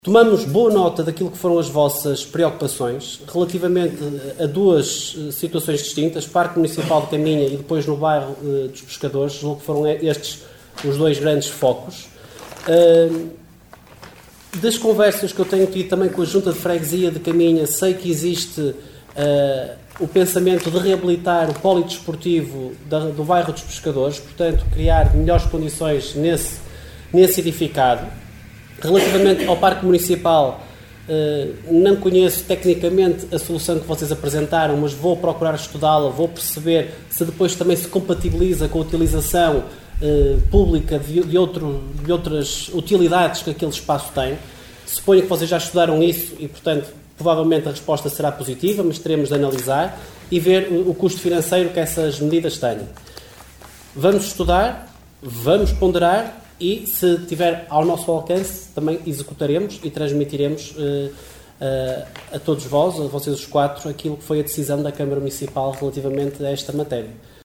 Excertos da última reunião do executivo caminhense realizado no dia 2 de abril.